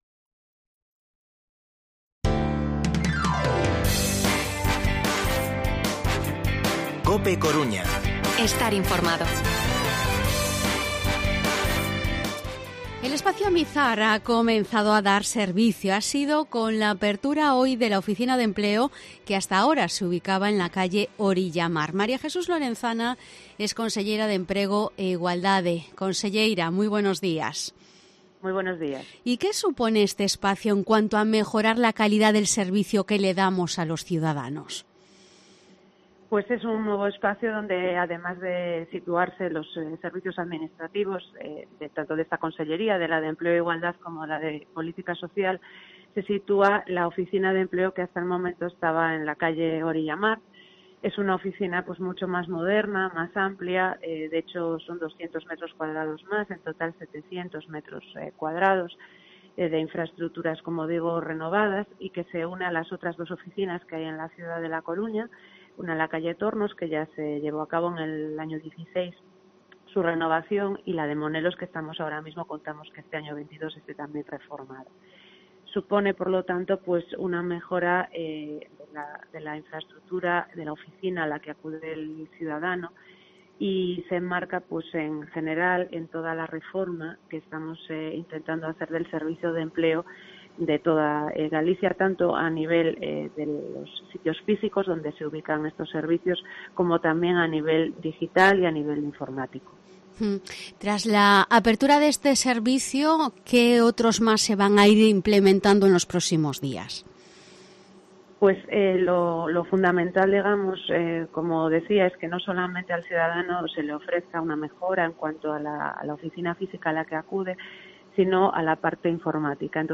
Entrevista conselleira emprego e igualdade. Recurso Xunta fondos europeos de empleo